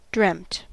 Ääntäminen
IPA : /ˈdɹɛmt/